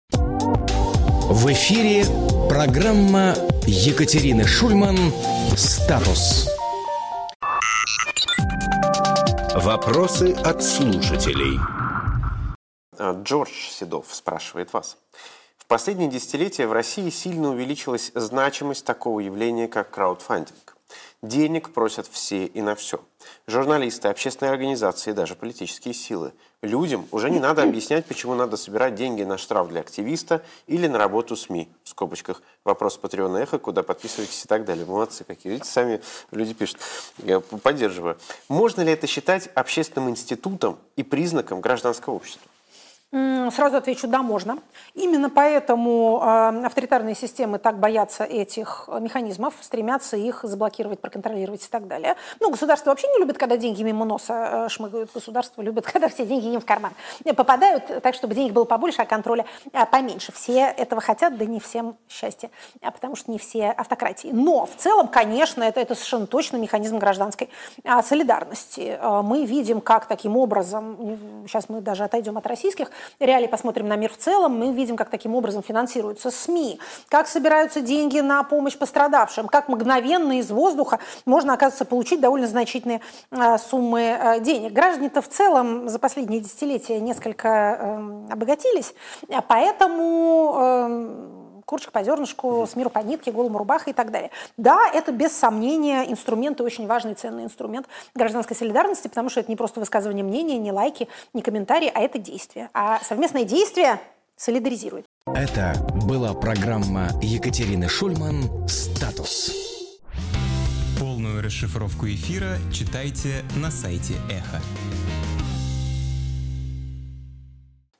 Екатерина Шульманполитолог
Фрагмент эфира от 27.01.2026